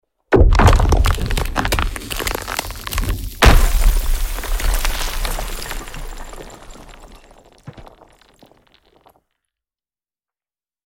Звук раскалывающегося на кусочки айсберга